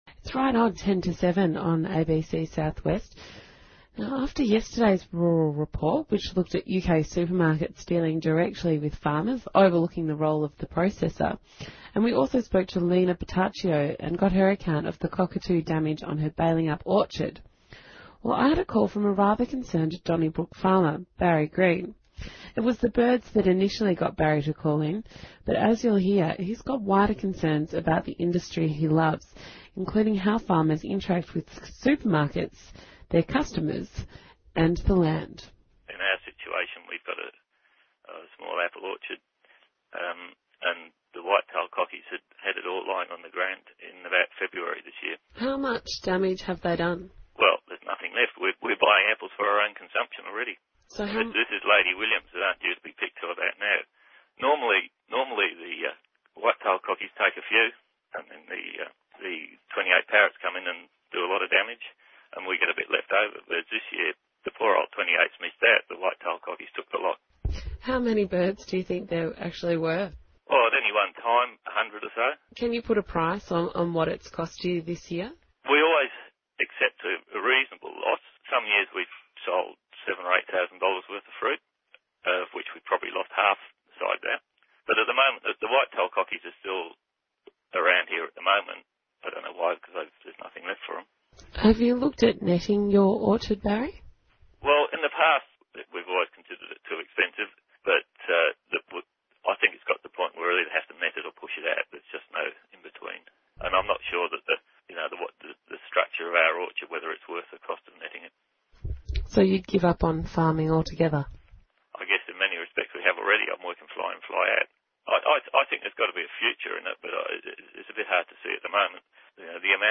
Other interviews on ABC radio have discussed the issue of orchard bird damage as well as the effect that the retail duopoly is having on producers